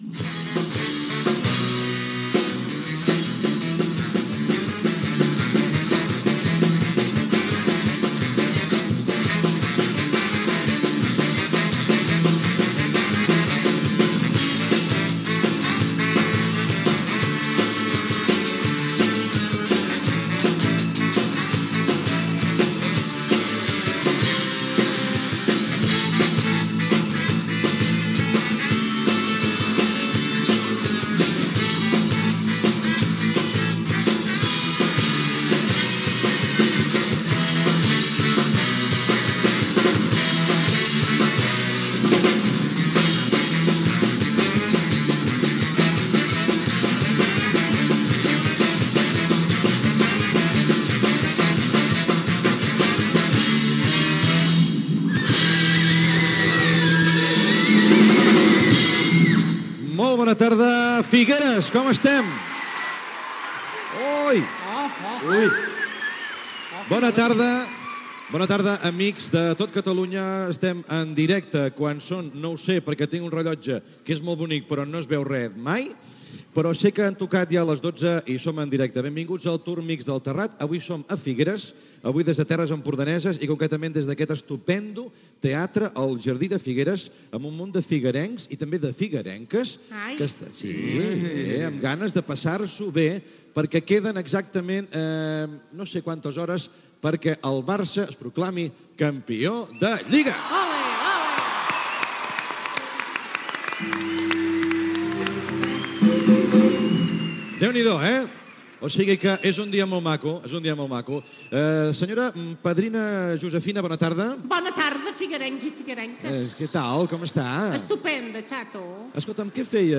"El tourmix del Terrat" fet des del teatre Jardí de Figueres.
Sintonia, interpretada en directe l'orquestra del programa "Sense títol s/n" de TV3, presentació, sumari de continguts, indicatiu del programa, cançó de Palomino dedicada a Figueres
Entreteniment
Buenafuente, Andreu